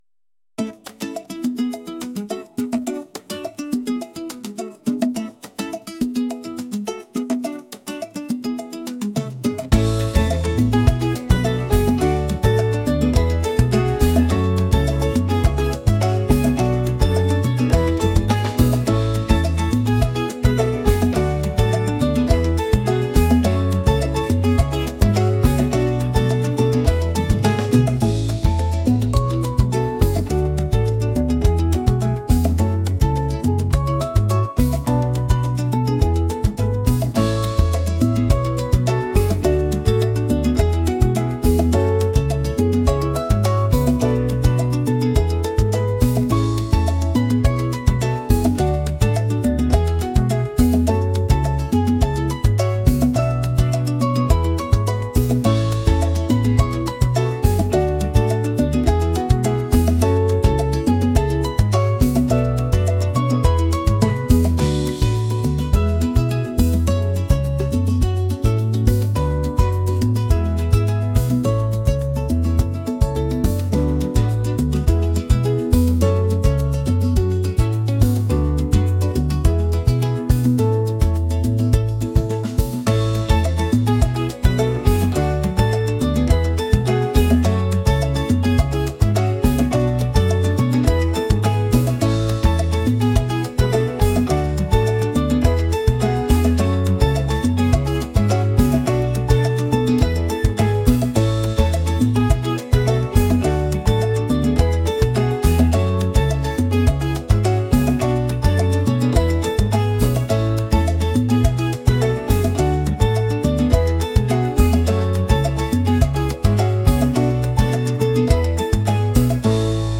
upbeat | pop | latin